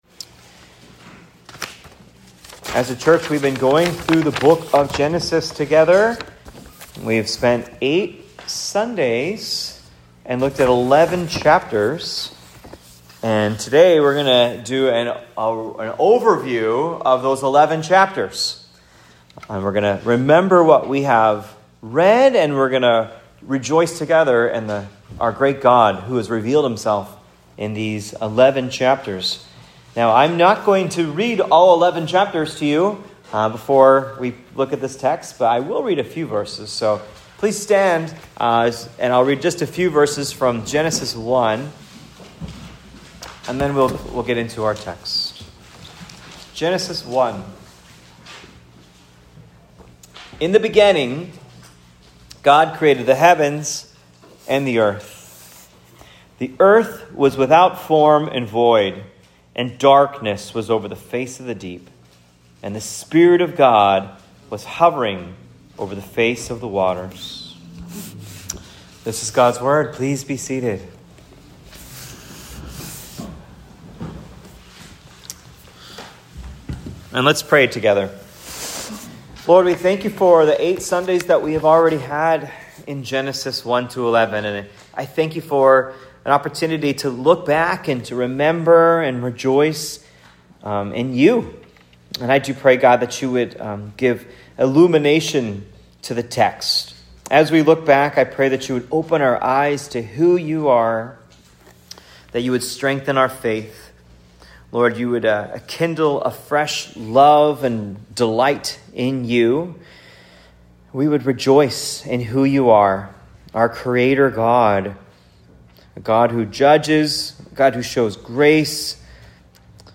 Genesis 1-11 Sermon. Here's a sermon summarizing the main ideas that we covered in Genesis 1-11 over the past 8 weeks.